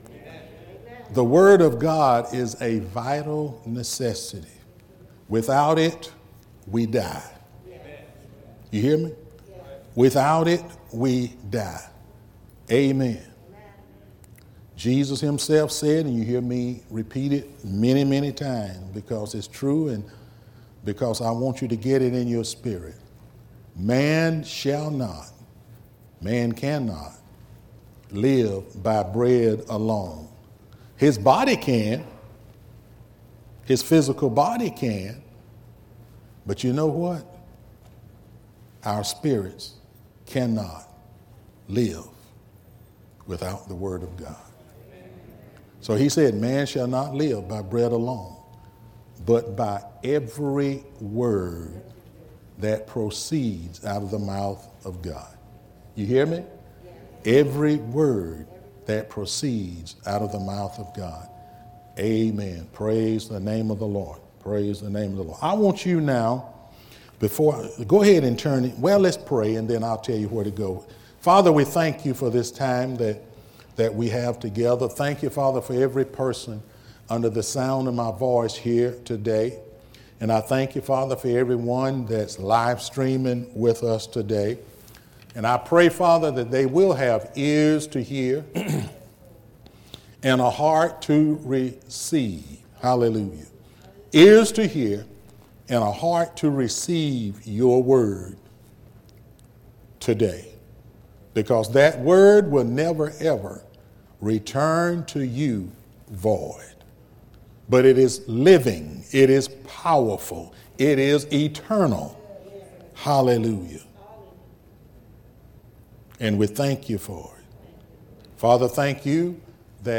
5-2-2021 Sunday School